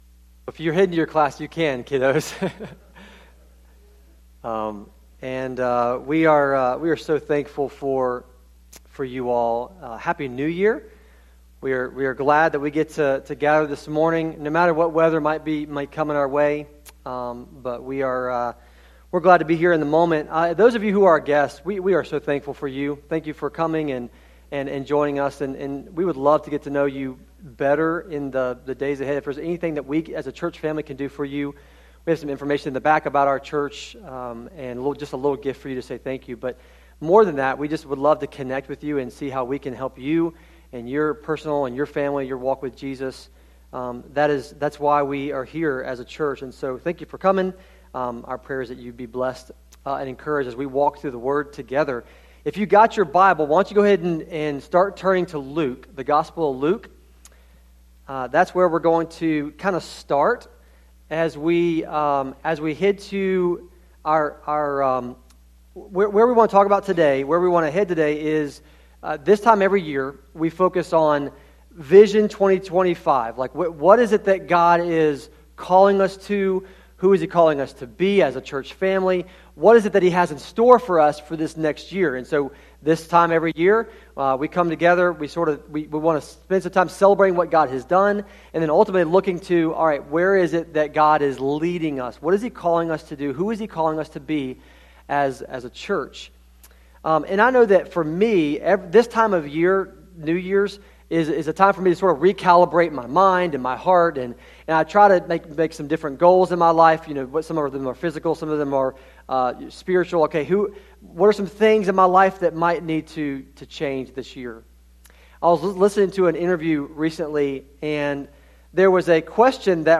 sermon-audio-trimmed.mp3